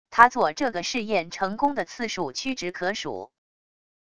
他做这个试验成功的次数屈指可数wav音频生成系统WAV Audio Player